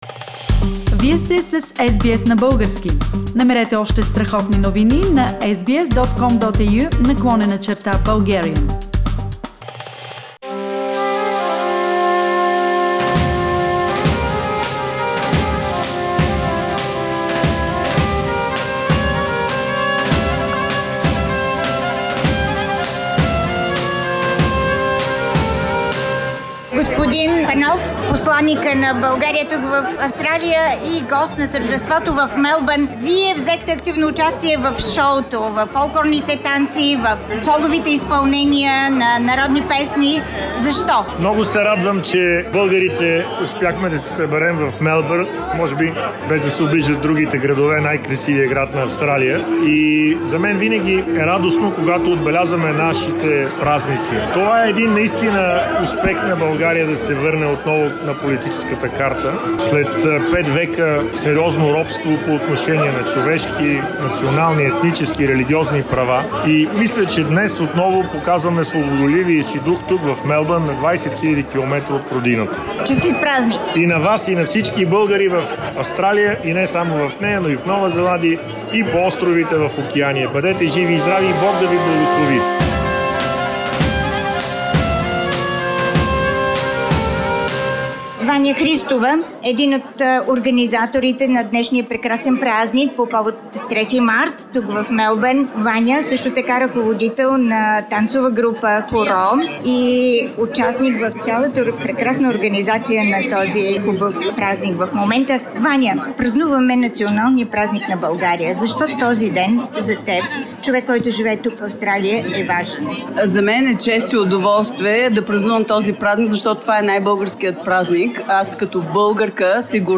Репортаж от тържеството, с което българите в Мелбърн на 27 февруари, неделя, отбелязаха Националният празник на България..